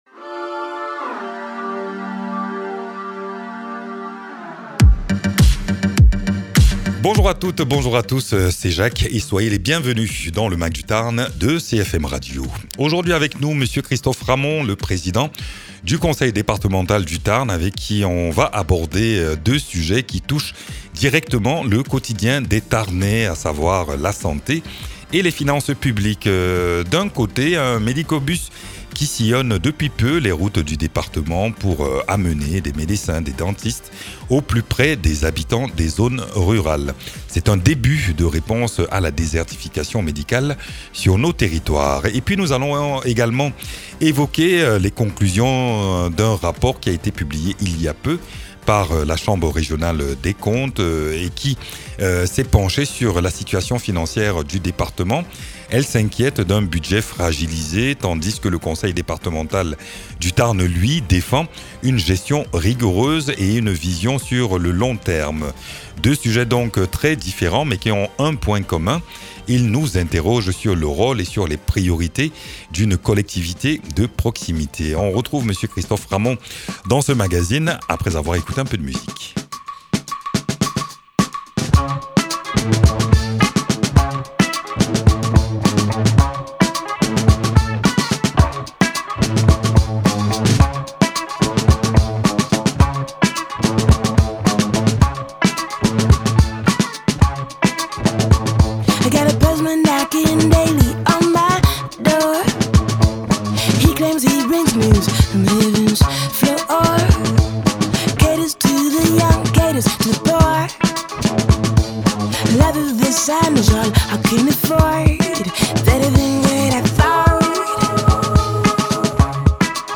Mags
Invité(s) : M. Christophe Ramond, Président du Département du Tarn.